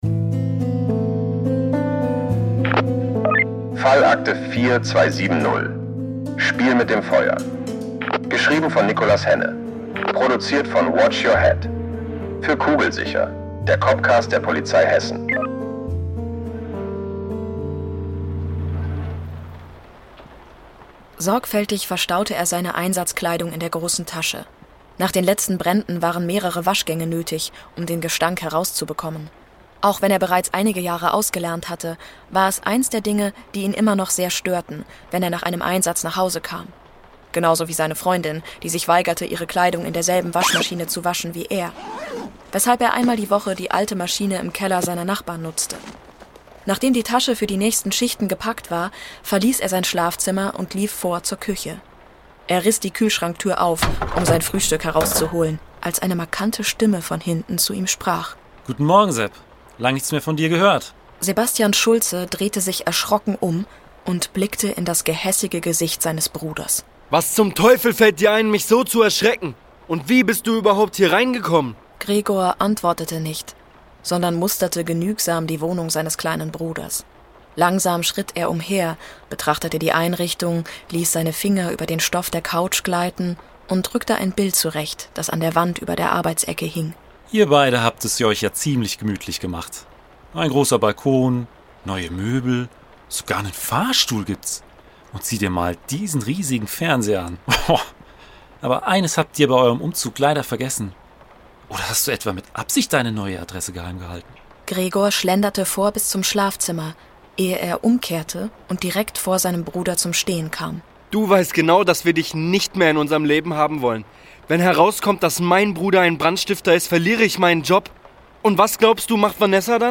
Ein spitzer Schrei durchdringt die Wohnung, während Gregor klar macht: Er ist bereit, alles zu zerstören.
Panische Schreie und ein Nachbar, der den Notruf absetzt, geben den Ermittlern den entscheidenden Hinweis. Blaulicht und Sirenen erfüllen die Straße, doch als sie eintreffen, ist es bereits zu still.